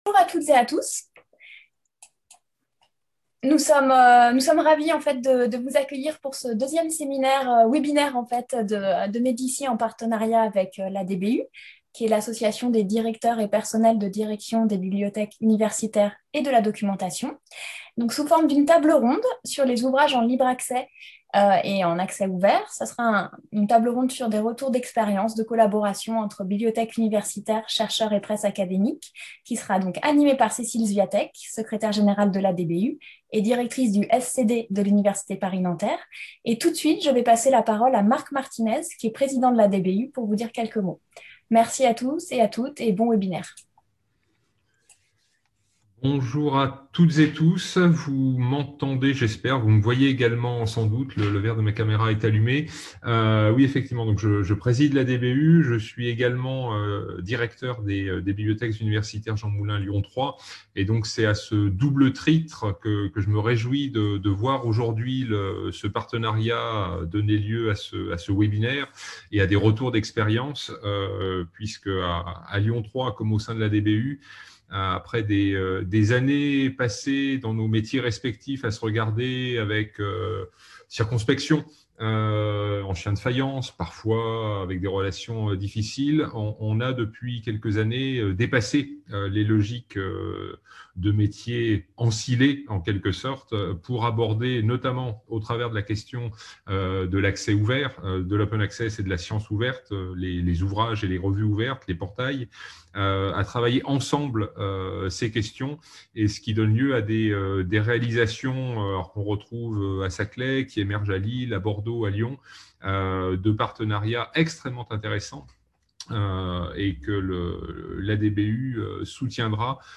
Webinaire